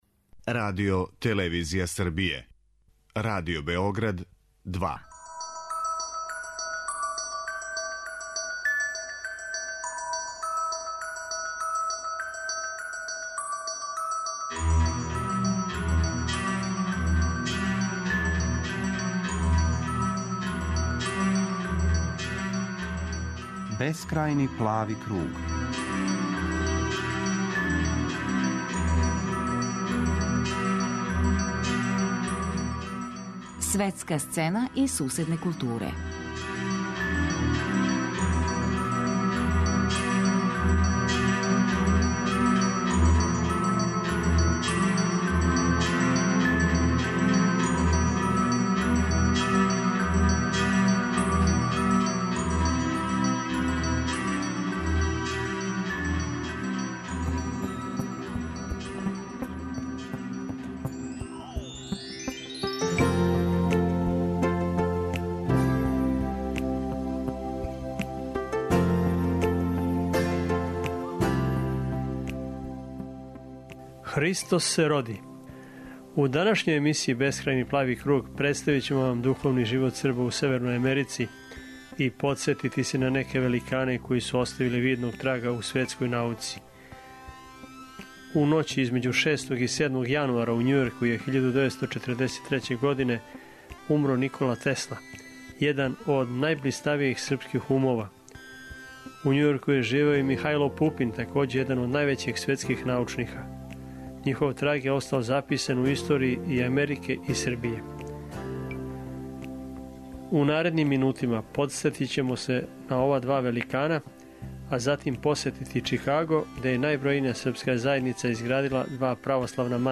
Божићно издање документарне емисије 'Бескрајни плави круг' говори о очувању вере и традиције cрпског народа на тлу Северне Америке.